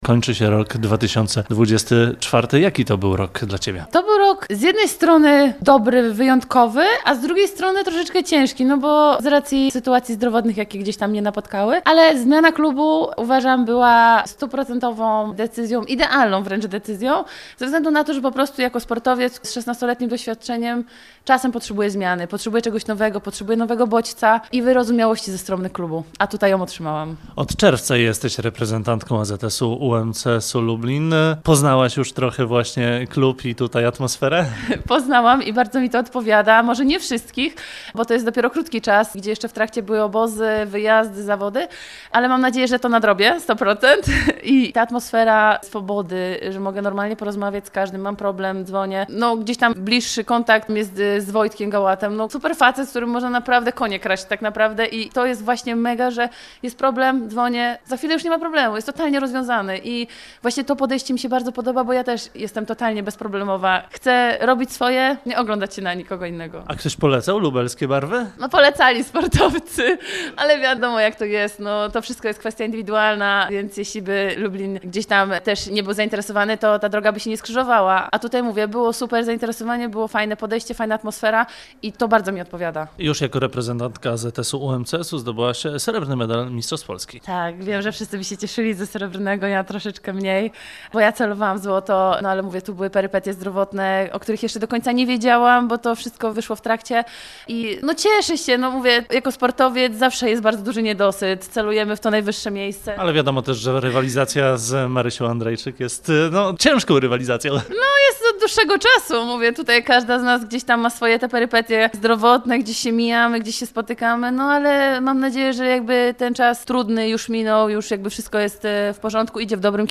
Rozmowa z Marceliną Witek-Konofał